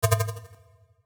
Buzz Error (1).wav